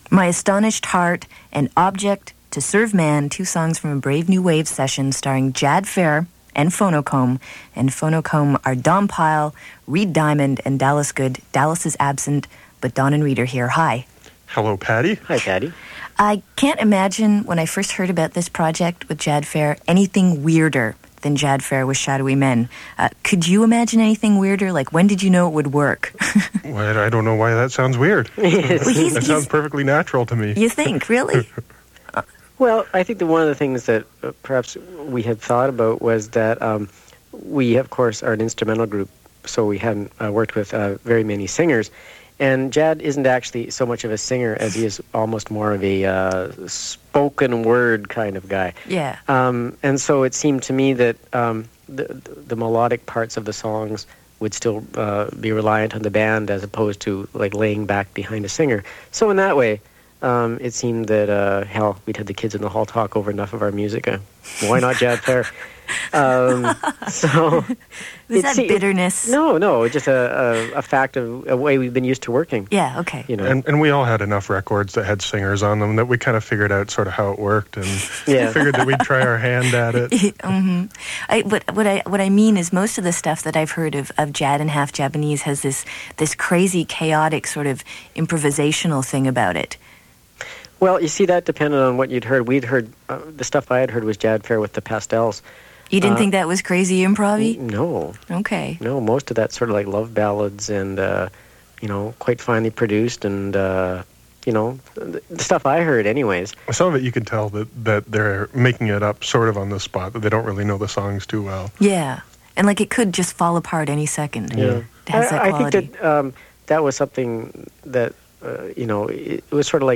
The interview was accompanied by what are described as recordings specifially for Brave New Waves , but they sound identical to the album versions. The interviews are combined below. Please note there were some dropouts in the recording, so the conversation will jump at a few points.